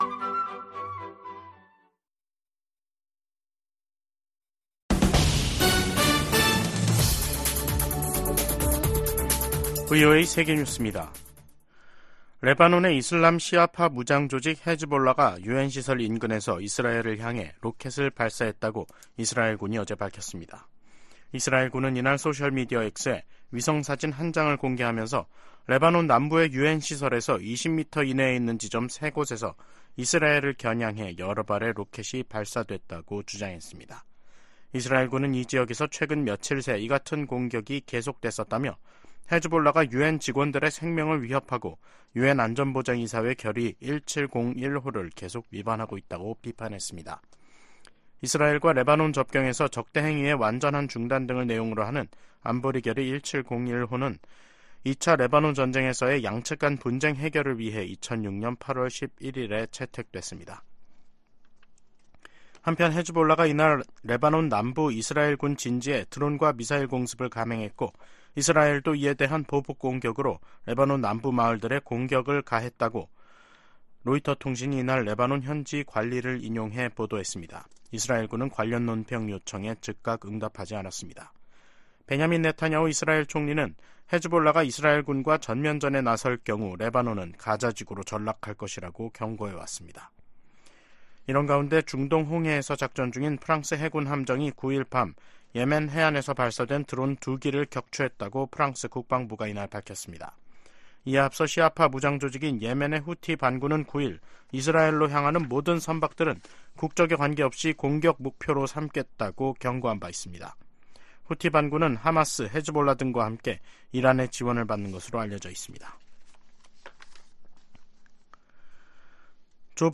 VOA 한국어 간판 뉴스 프로그램 '뉴스 투데이', 2023년 12월 11일 2부 방송입니다. 미국과 한국, 일본이 새 대북 이니셔티브를 출범하면서 북한 정권의 핵과 미사일 기술 고도화의 자금줄 차단 등 공조를 한층 강화하겠다고 밝혔습니다. 미 상하원의원들이 중국 시진핑 정부의 탈북민 강제 북송을 비판하며, 중국의 인권이사국 자격 정지 등 유엔이 강력한 대응을 촉구했습니다. 영국 의회가 북한의 불법 무기 개발과 인권 문제 등을 다룰 예정입니다.